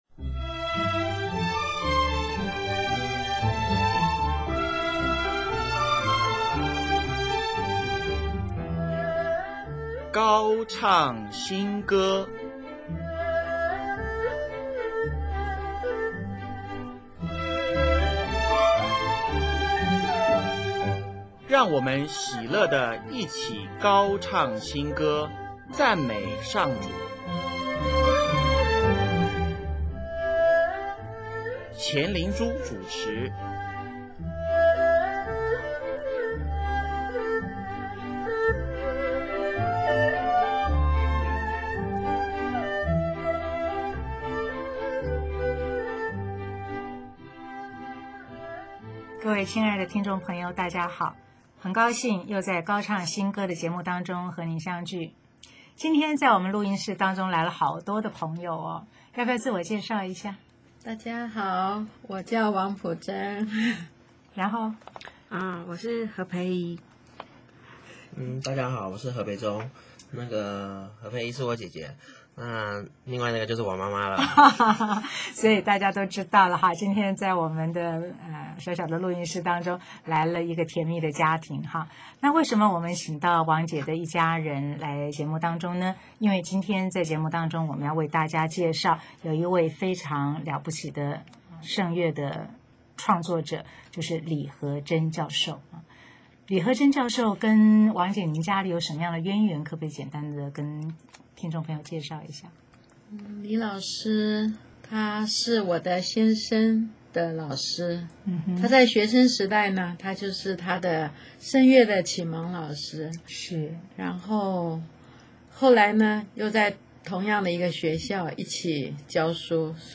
李河珍教授用中国五声音阶创作了整套的弥撒曲，配合文字的音韵，选择调性，作品充满华人风味和特殊的东方色彩，他使用的音域，让一般人皆可朗朗上口。本集介绍李教授生前创作的“垂怜曲”、“光荣颂”和“哈肋路亚”